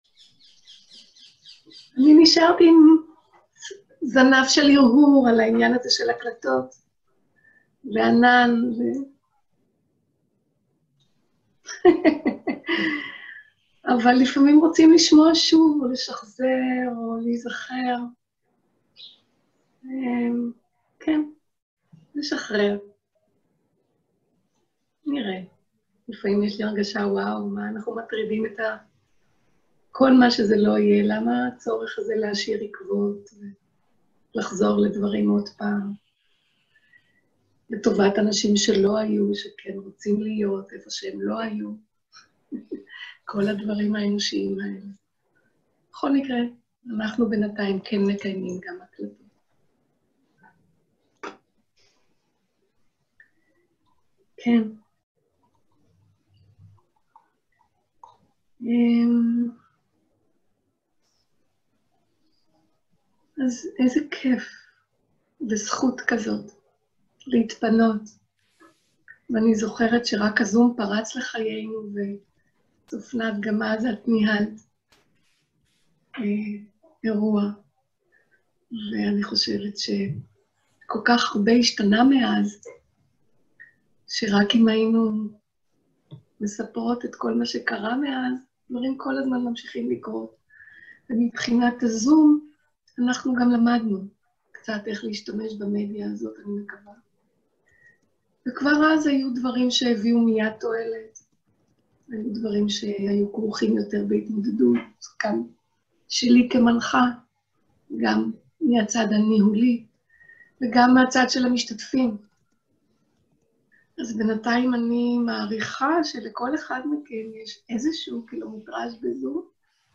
סוג ההקלטה: שיחת פתיחה
עברית איכות ההקלטה: איכות גבוהה מידע נוסף אודות ההקלטה